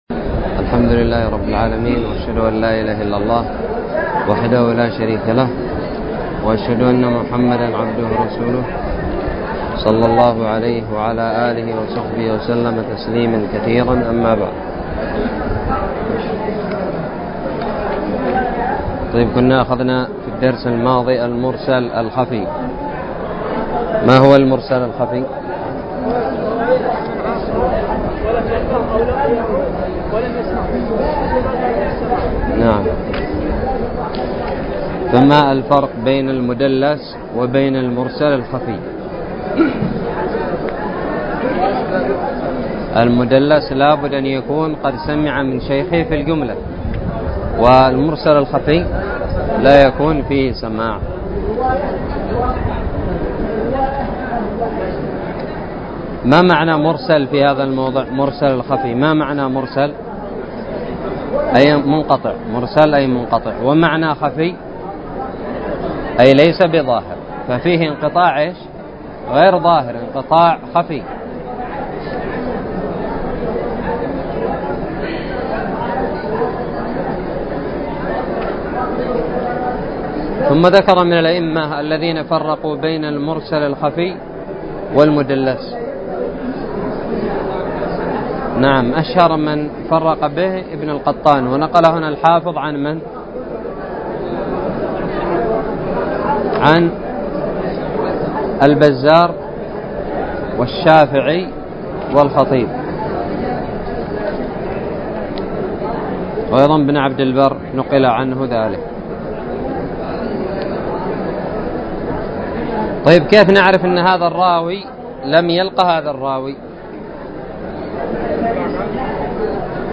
الدرس الثالث والعشرون من شرح كتاب نزهة النظر
ألقيت بدار الحديث السلفية للعلوم الشرعية بالضالع